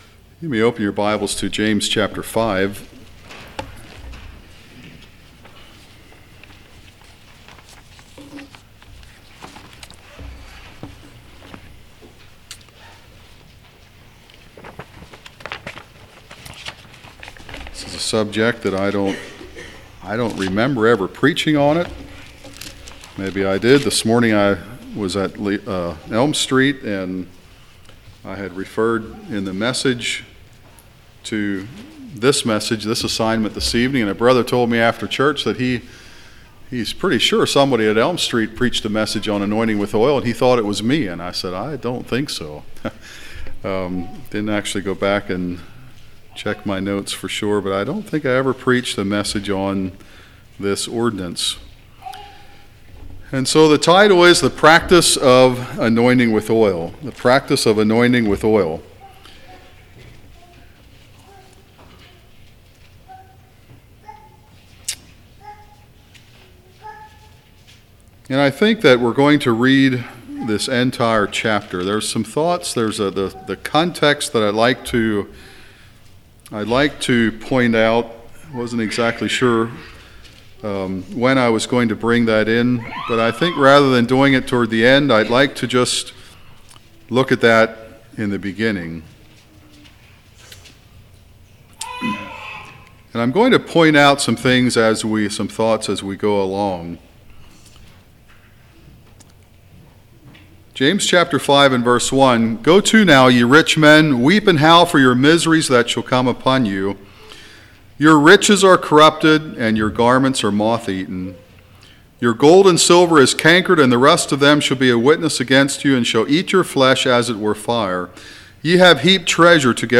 Congregation: Susquehanna Valley